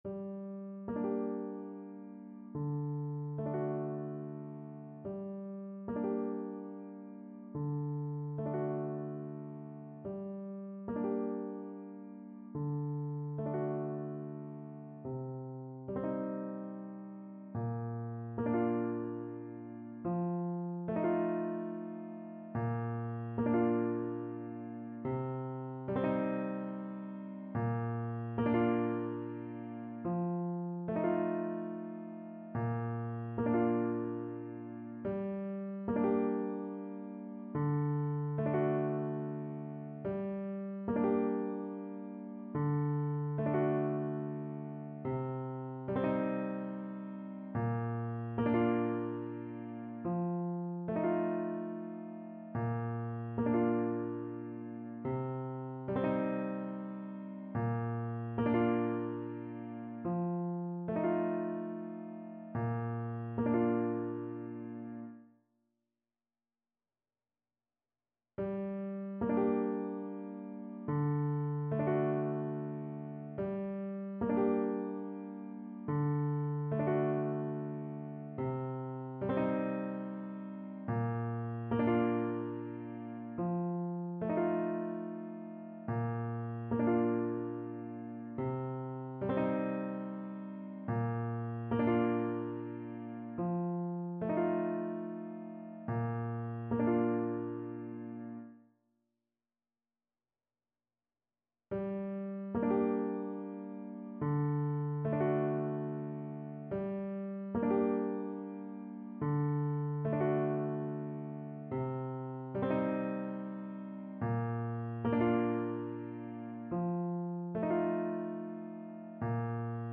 Symulacja akompaniamentu